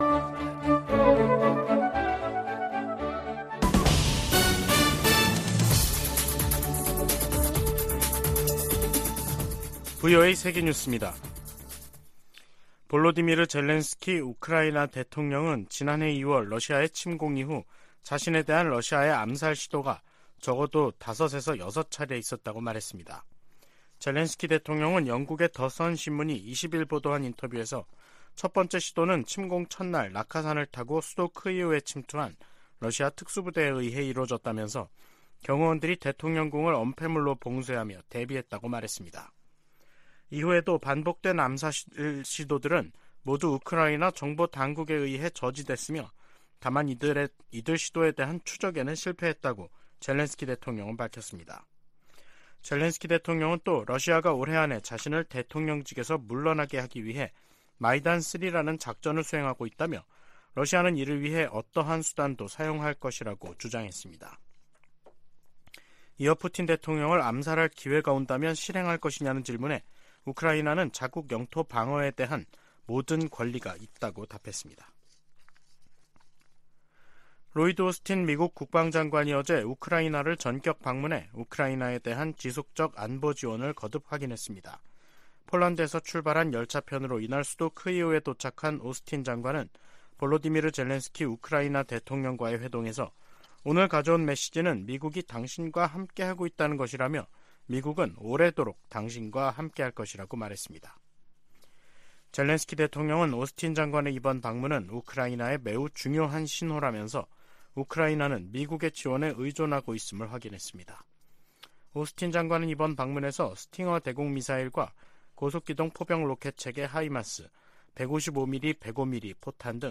VOA 한국어 간판 뉴스 프로그램 '뉴스 투데이', 2023년 11월 21일 3부 방송입니다. 국제해사기구(IMO)는 북한이 오는 22일부터 내달 1일 사이 인공위성 발사 계획을 통보했다고 확인했습니다. 북한의 군사정찰위성 발사 계획에 대해 미 국무부는 러시아의 기술이 이전될 가능성을 지적했습니다. 한국 정부가 남북 군사합의 효력 정지를 시사하고 있는 가운데 미국 전문가들은 합의 폐기보다는 중단했던 훈련과 정찰 활동을 재개하는 편이 낫다고 진단했습니다.